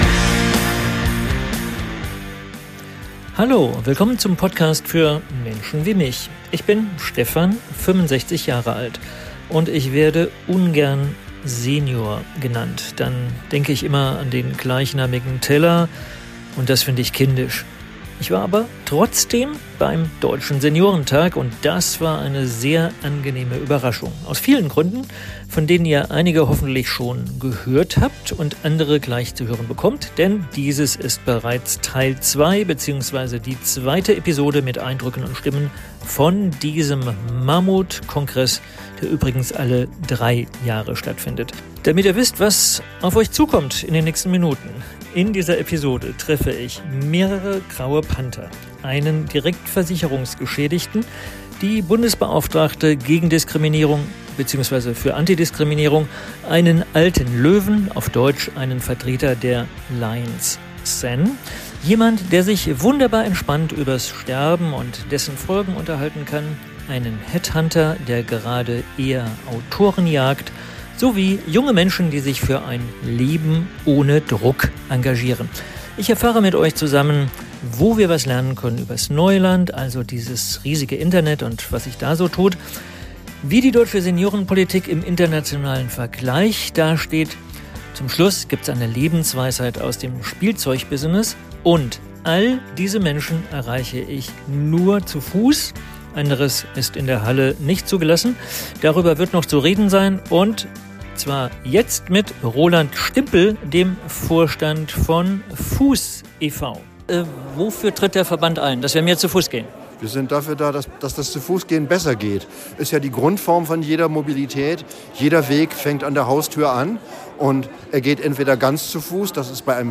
In dieser Episode begegne ich beim Dt. Seniorentag u.a. - grauen Panthern - der Bundesbeauftragten gegen Diskriminierung - Einem Headhunter der gerade Autoren jagd - sowie weiteren 7 Menschen, die für ganz unterschiedliche Facetten des älter werdens stehen - und ich erfahre von einem Ereignis, über...